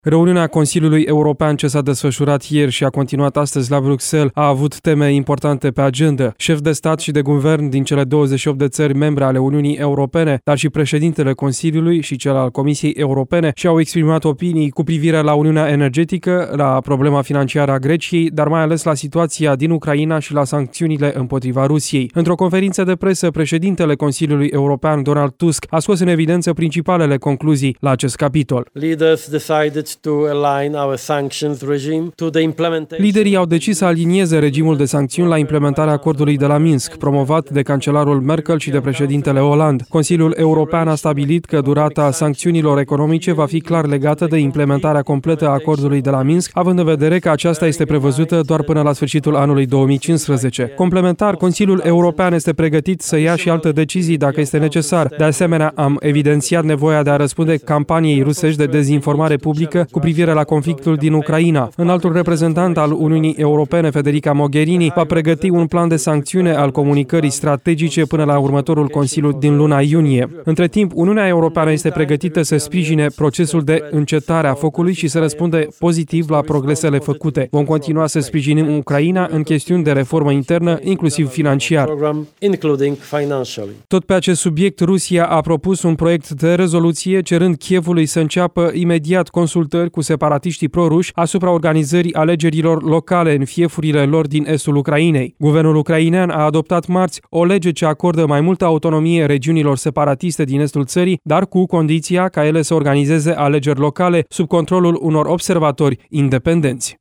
Într-o conferință de presă, președintele Consiliului European, Donald Tusk, a scos în evidență principalele concluzii la acest capitol.